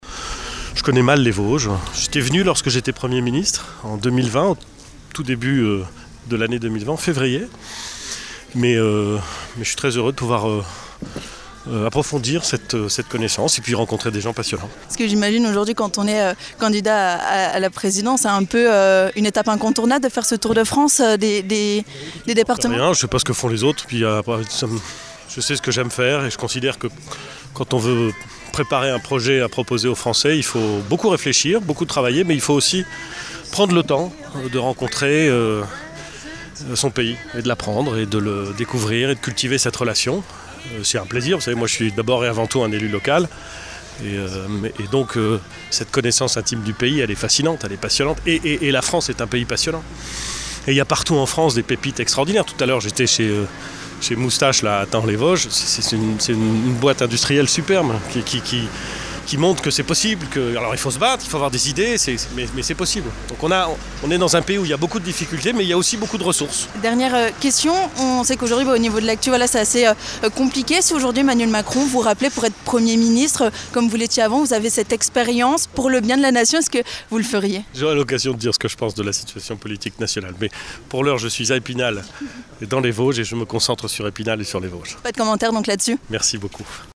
Le candidat à la prochaine présidentielle a notamment visité l'entreprise Moustache Bikes à Thaon-les-Vosges, puis la Basilique à Epinal, ainsi que le Musée de l'Image et la BMI. L'occasion pour nous de lui tendre notre micro.